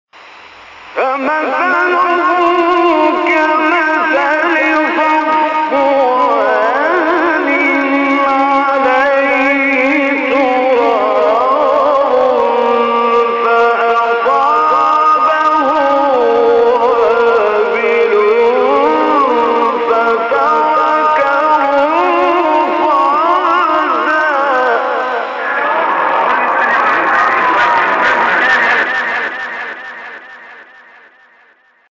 گروه شبکه اجتماعی: مقاطعی صوتی از تلاوت قاریان برجسته مصری را می‌شنوید.